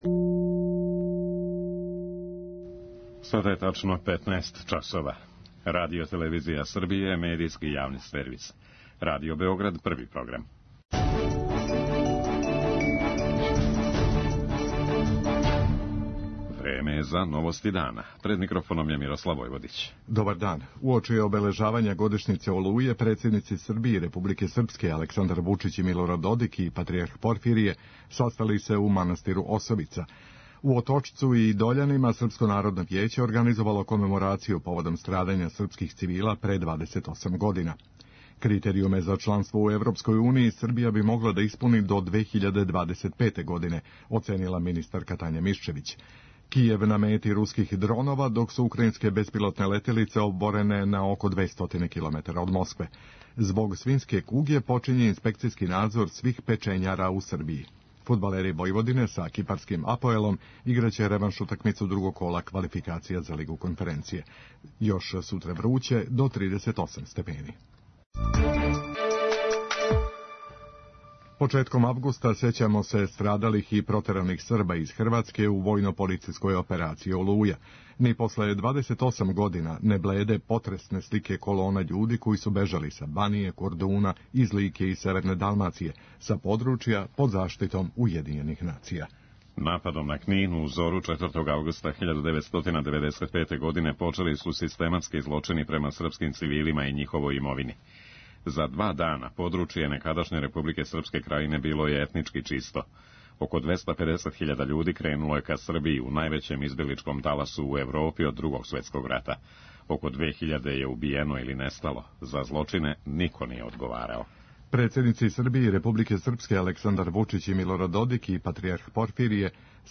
Predstavnici MIVUS-a su pozvani da na Radio Beogradu govore o izolaciji, novitetima u energetskoj efikasnosti, zaštiti zgrada od požara i subvencijama od strane države ali i Evropske Unije.